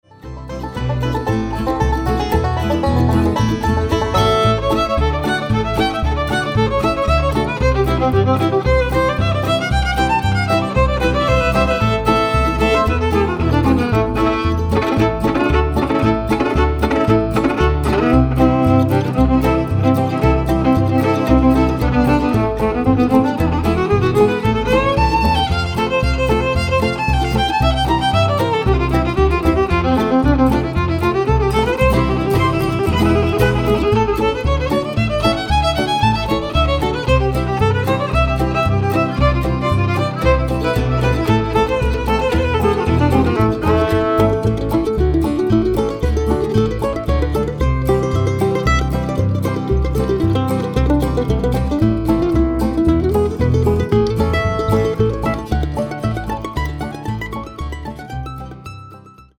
An instrumental album
mostly from the Bluegrass and Old-Time repertoire